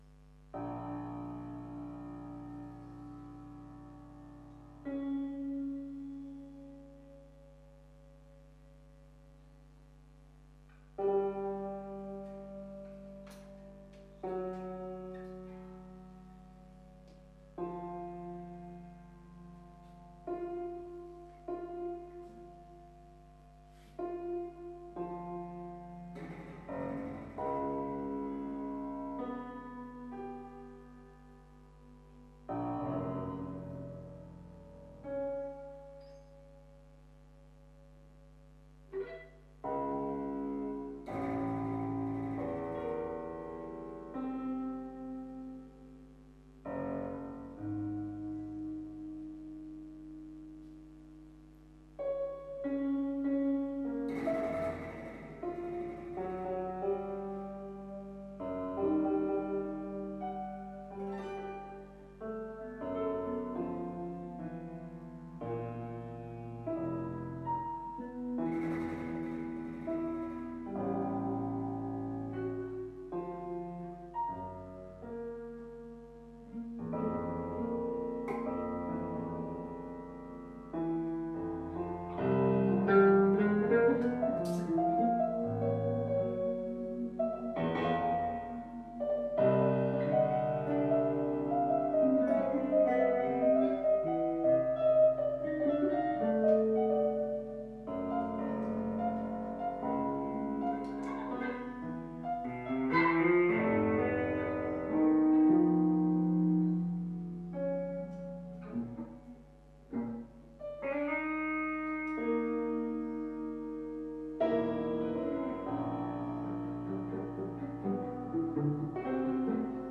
Saxophon/Samples
E-Gitarre